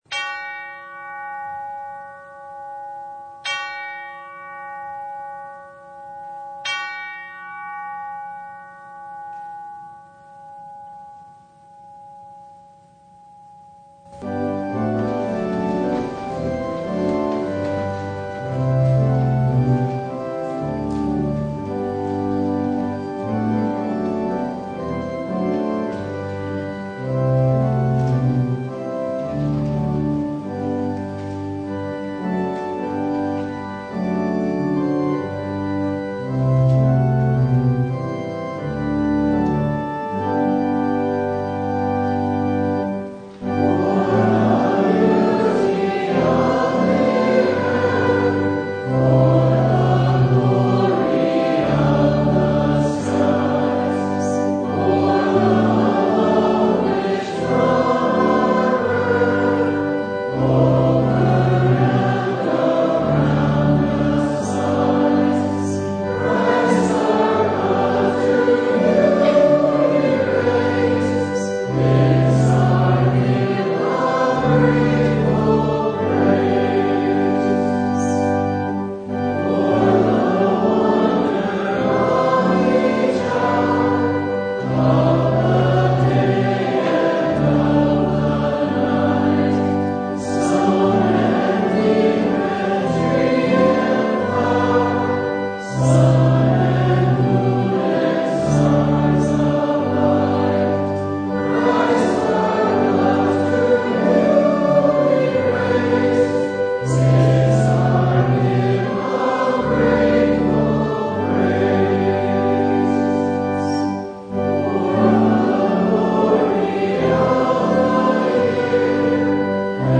Service Type: Sunday
Full Service